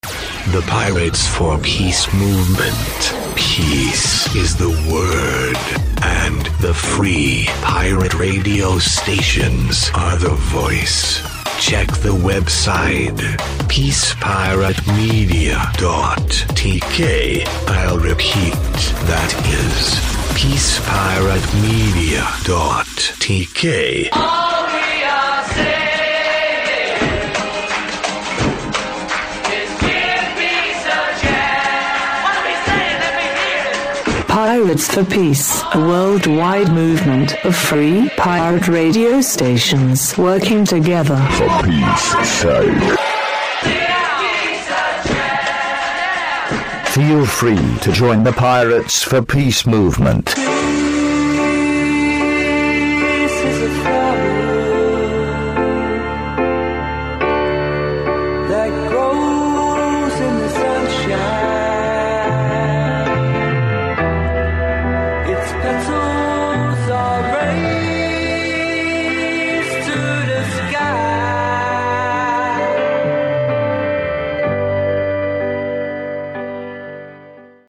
©2018 – PROMO – P4P – Updated Promo + Website Info (male) 01+A.mp3